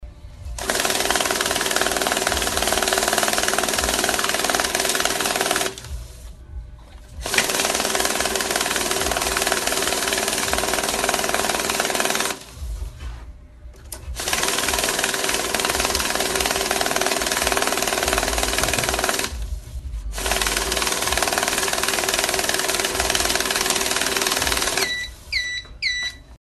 Звуки счётной машинки
Счётная машинка отсчитывает по сто банкнот